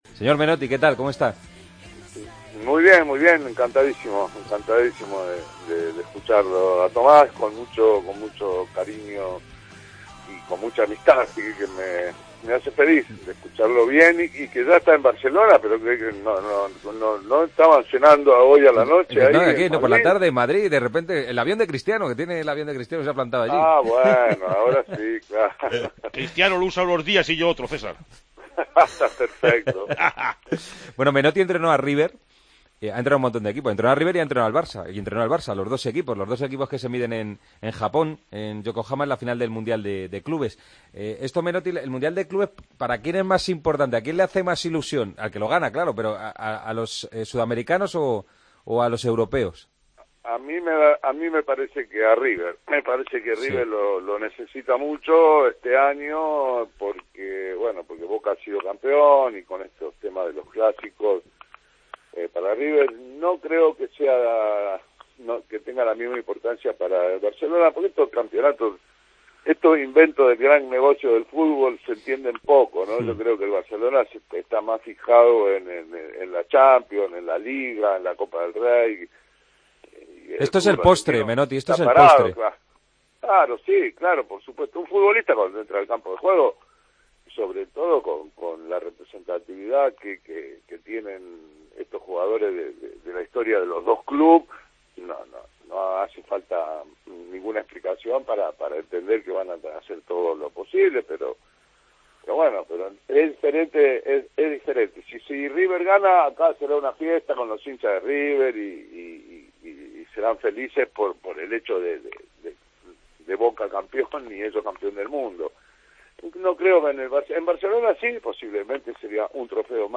AUDIO: El extécnico argentino pasa por COPE para analizar la final del Mundial de Clubes de este domingo entre Barcelona y River Plate.